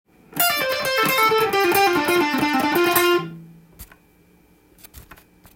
エレキギターで弾ける【ランディーローズフレーズ集】tab譜
フレーズ集は、全てDm　keyで使用できるものになっています。
②のフレーズもDmペンタトニックスケールの繰り返しフレーズになります。
ピッキングが単純なので合わせやすく準備体操にもなりそうです。